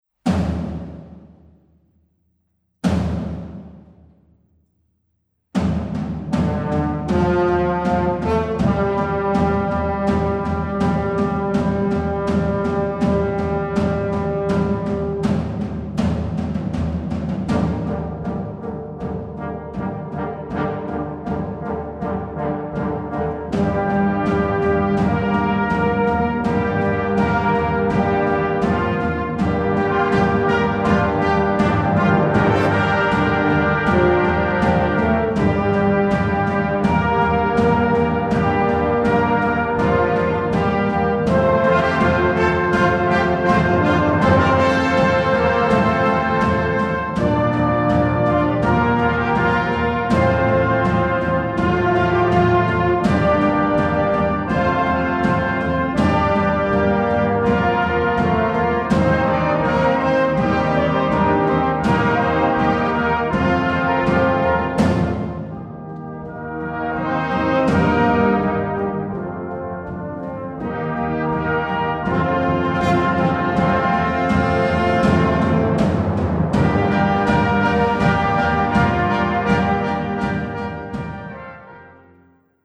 Komponist: Traditionell
Besetzung: Blasorchester
Traditional Chinese Tune.